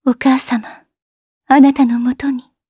Isaiah-Vox_Dead_jp.wav